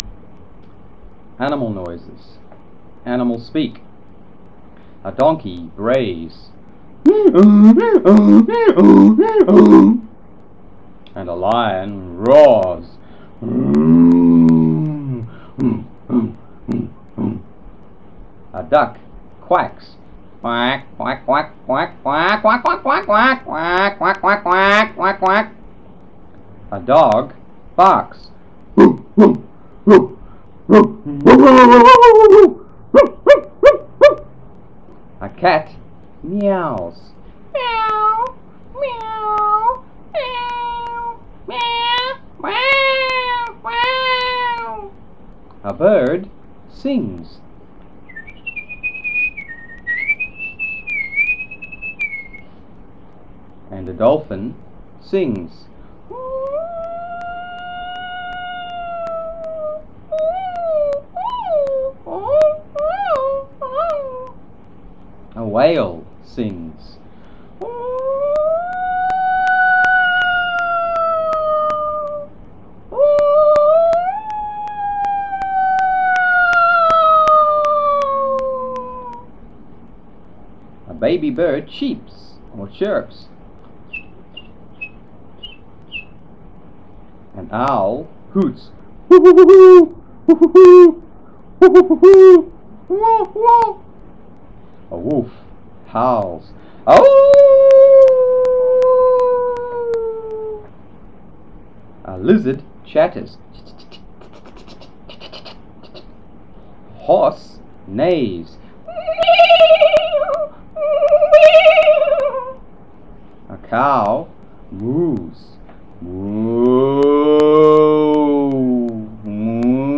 1. A donkey brays
2. A lion roars
3. A duck quacks
4. A dog barks
5. A cat meows
10. An owl hoots
11. A wolf howls
13. A horse neighs or whinnies
14. A cow moos
27. A hyena laughs
RESOURCE-ANIMAL NOISES.WAV